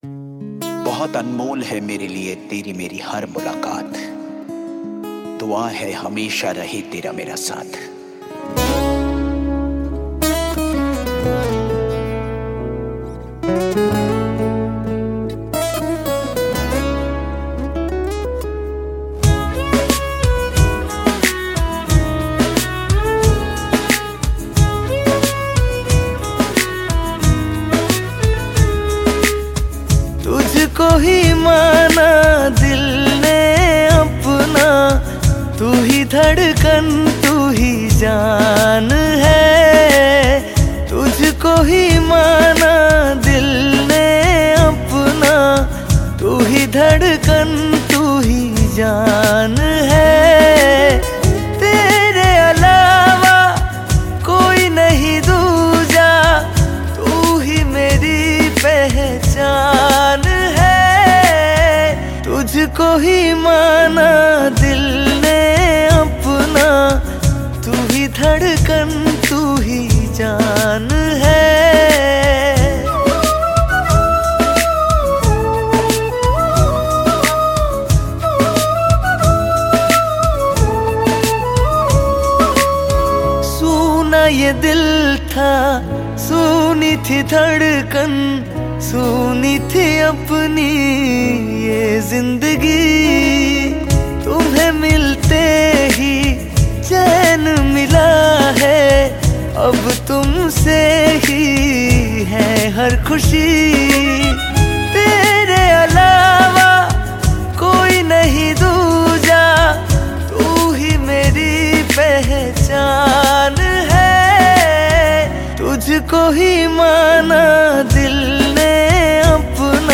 Hindi Pop Album Songs 2023